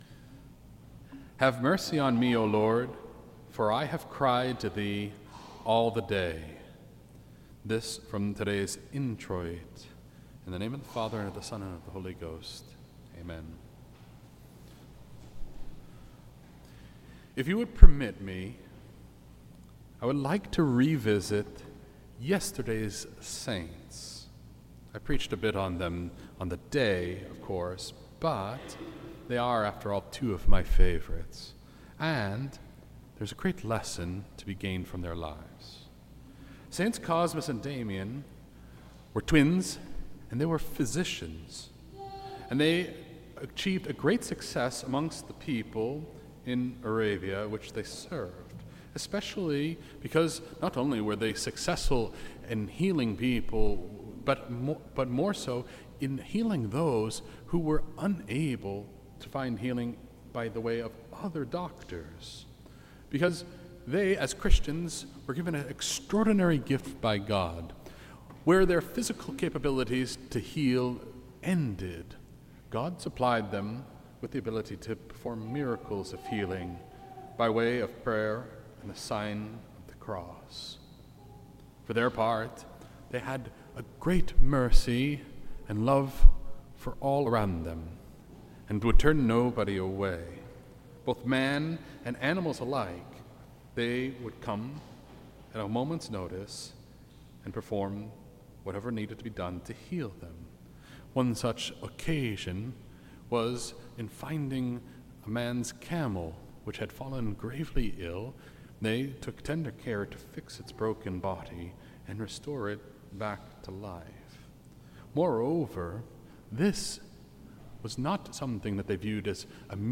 This entry was posted on Sunday, September 28th, 2025 at 3:10 pm and is filed under Sermons.